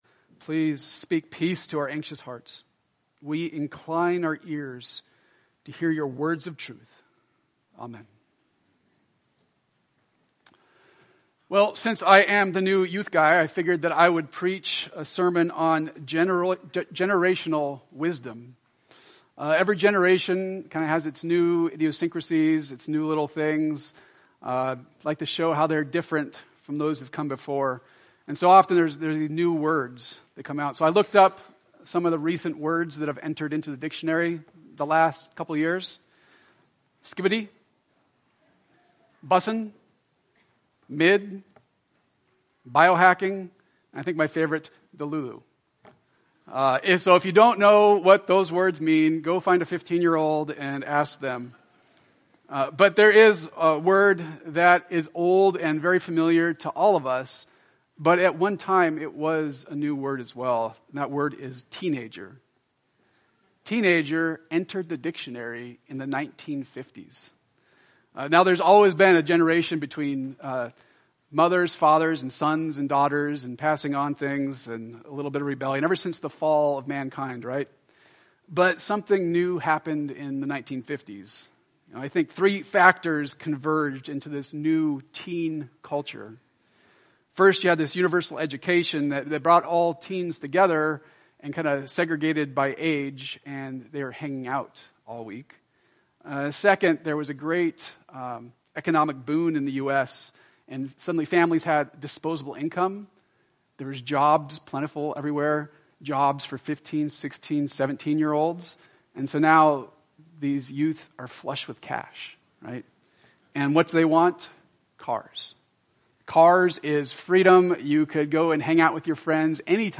Type Morning